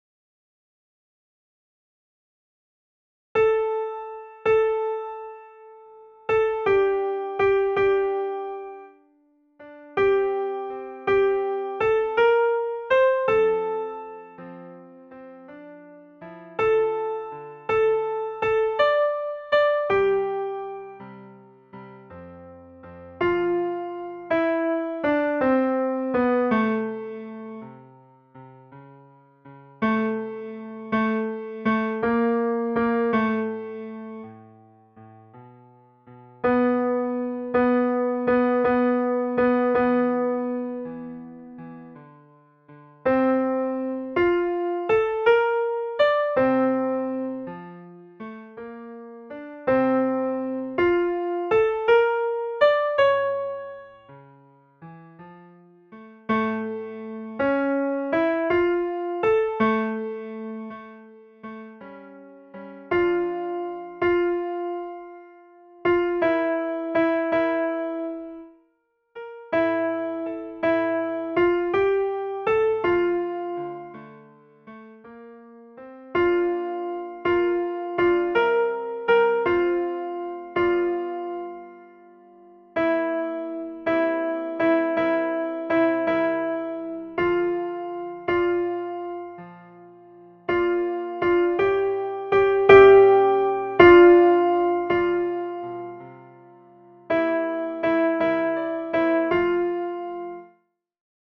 CHOEUR ET ECHO
Alti
le_vent_dans_la_foret_alti.mp3